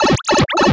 The sound heard when entering a Warp Pipe in Paper Mario: Sticker Star
Ripped from the game files
PMSS_Pipe_In.wav.mp3